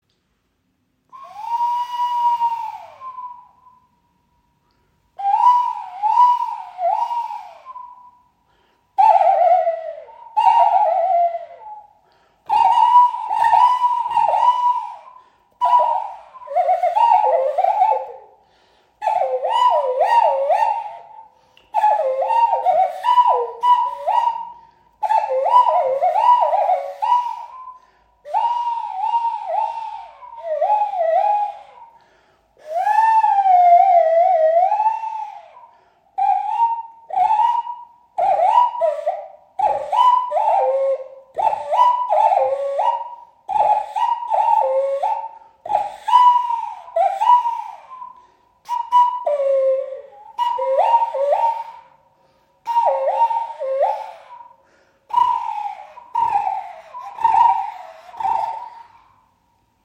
Uhu Bambus-Pfeife – Täuschend echter Uhu-Ruf | Natur entdecken • Raven Spirit
Effekt Flöte aus Bambus | Stimme des Waldes
Mit dieser kleinen Bambuspfeife rufst Du täuschend echt einen Uhu oder andere Eulen. Einfach in sanften Stössen blasen, unten mit der Hand öffnen oder schliessen – und schon erwacht der nächtliche Ruf zum Leben.
Ein paar sanfte Atemstösse, die Hand über die Öffnung gelegt und schon antwortet der Bambus mit dem faszinierenden Klang des Uhus.